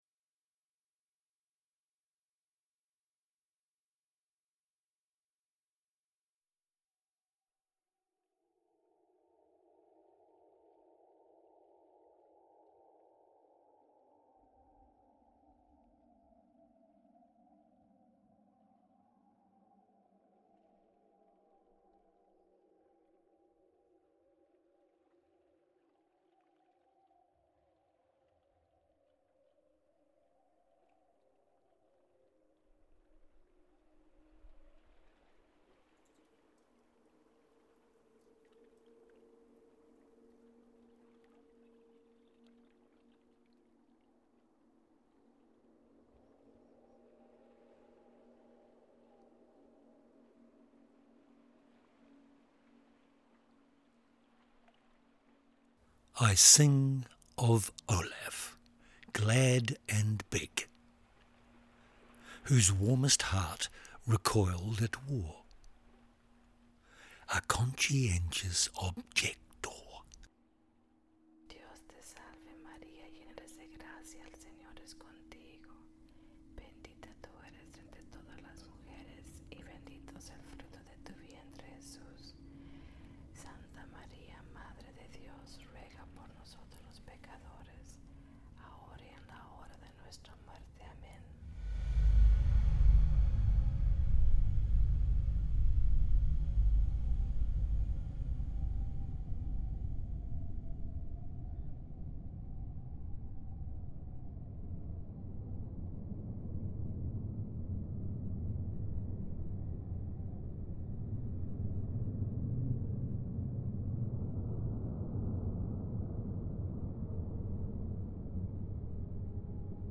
This work relies upon the very fine software available in Altiverb. It is enormously instrument specific, and so the stereo bounce provided here is merely a glimpse of the actual work.